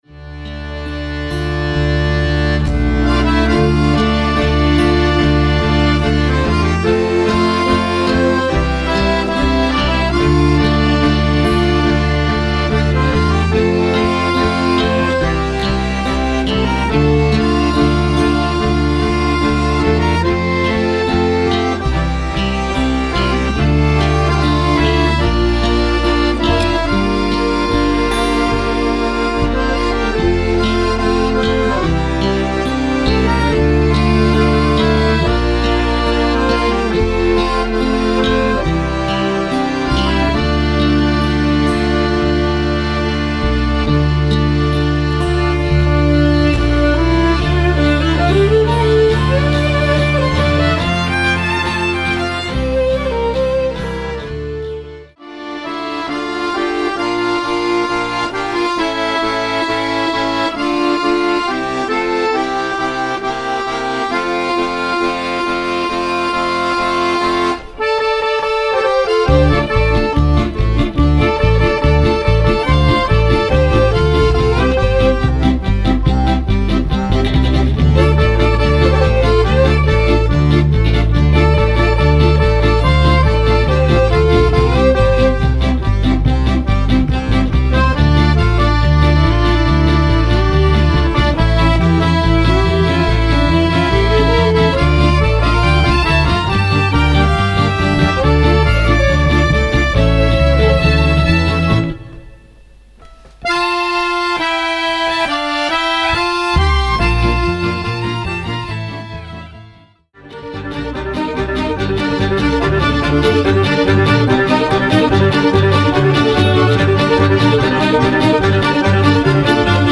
Een mix van franse melodieën met swingende klassiekers
KLASSIEKERS IN EEN NIEUW JASJE
Klassieker Compilatie